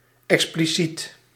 Ääntäminen
US : IPA : [ik.ˈspli.sit]